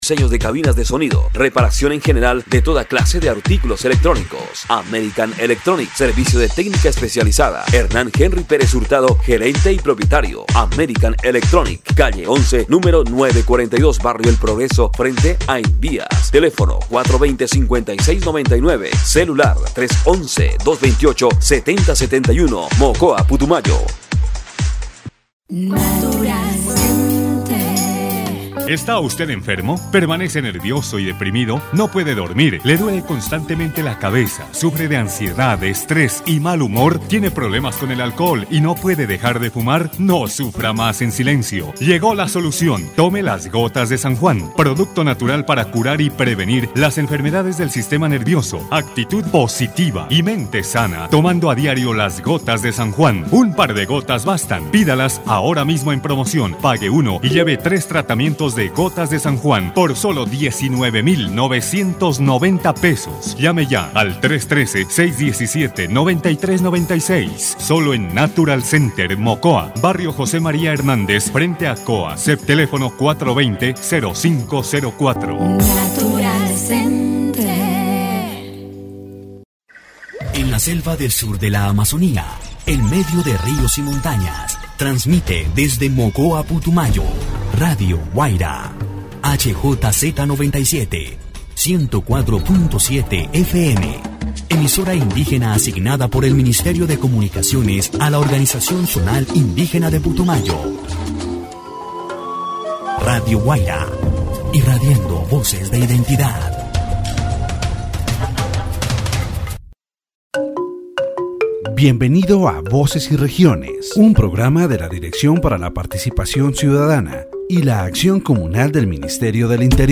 The radio program "Voces y Regiones" on Radio Guaida 104.7 FM, run by the Ministry of the Interior, focuses on youth participation in politics.